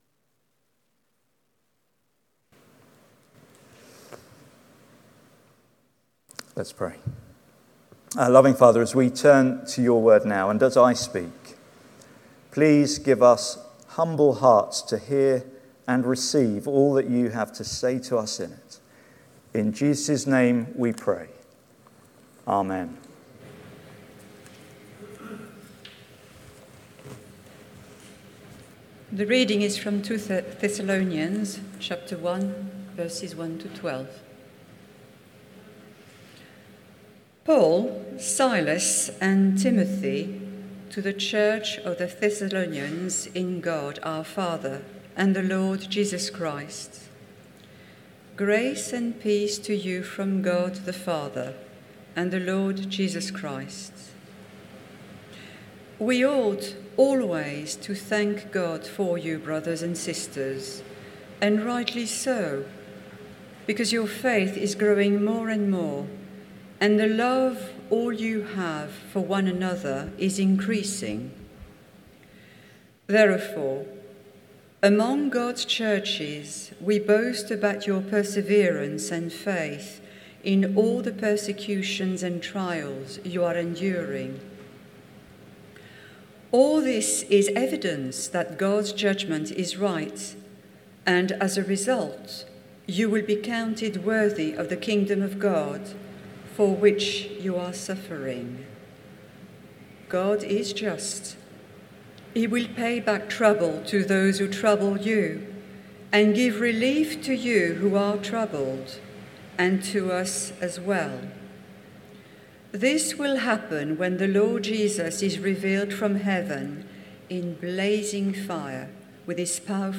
Sermon Transcript Study Questions